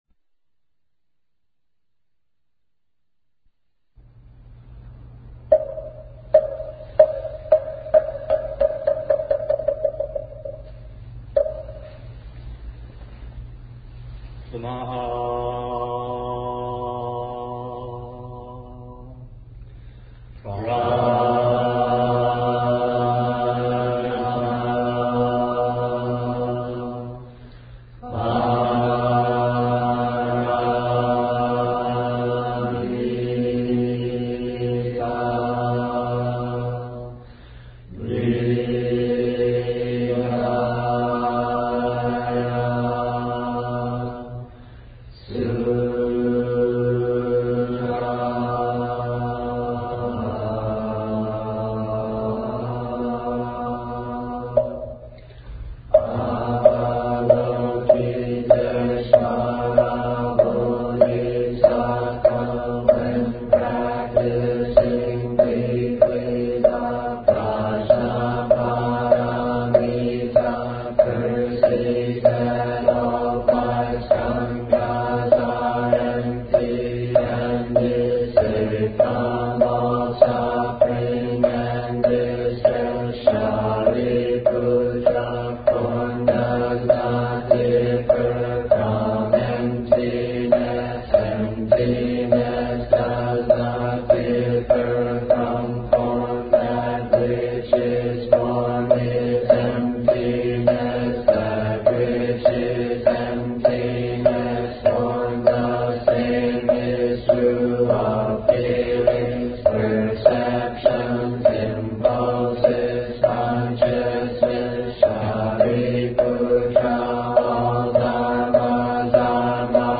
Chanting the Sutra
The Heart Sutra is chanted directly after Zazen.
Grey is for lower pitch voice and Yellow for upper pitch voice
HeartSutraChantEnglish.mp3